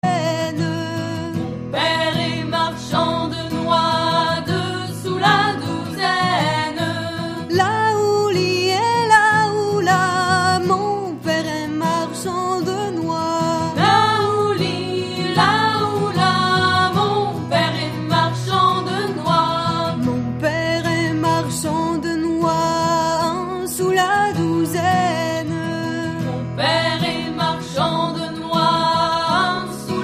Musique : Traditionnel
Origine : Bretagne/Pays de Saint-Malo